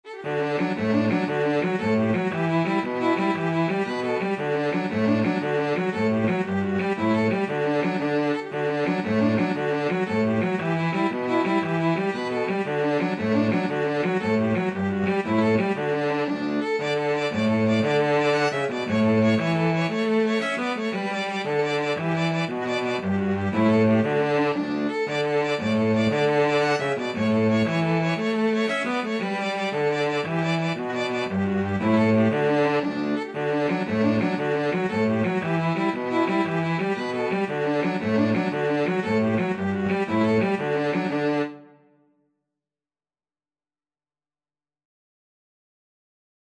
Free Sheet music for Violin-Cello Duet
D major (Sounding Pitch) (View more D major Music for Violin-Cello Duet )
6/8 (View more 6/8 Music)
Allegro .=c.116 (View more music marked Allegro)
Traditional (View more Traditional Violin-Cello Duet Music)